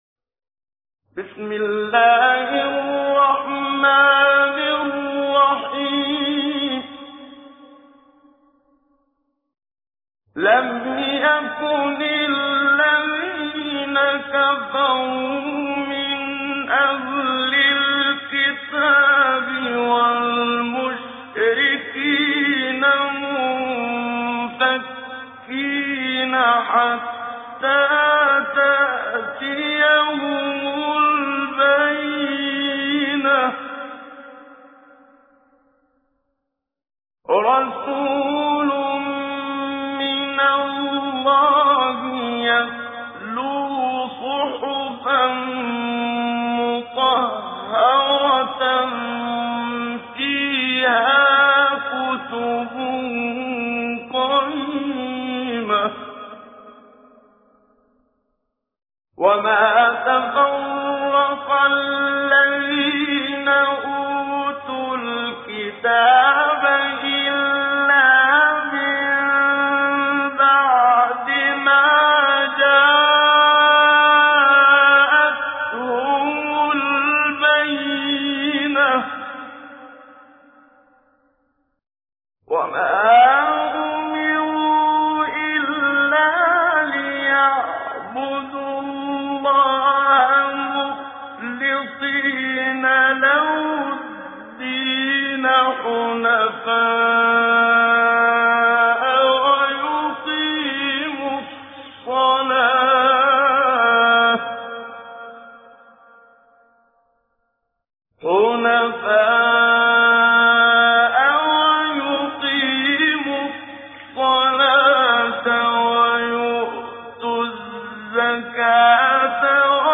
تجويد
سورة البينة الخطیب: المقريء الشيخ محمد صديق المنشاوي المدة الزمنية: 00:00:00